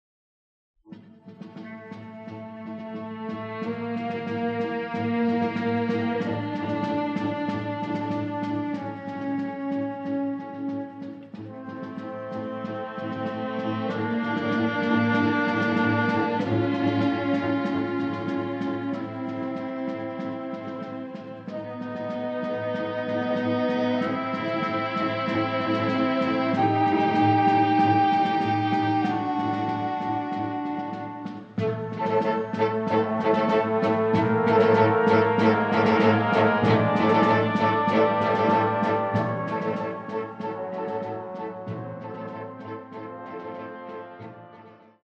A big, beautiful, emotional and elegant score.